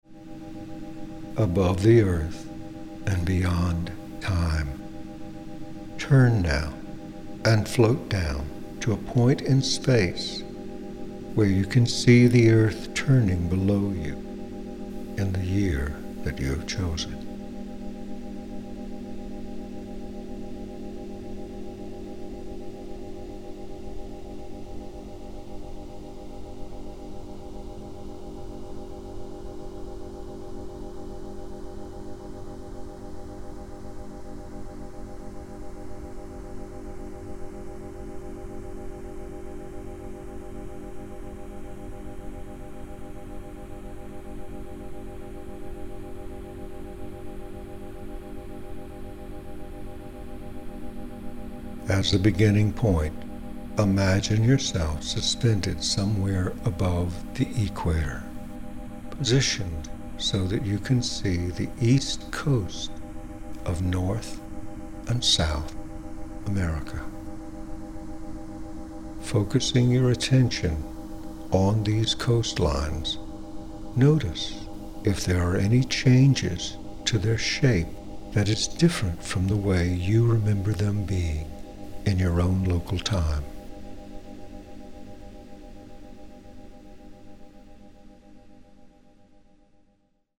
Verbální vedení: Anglické verbální vedení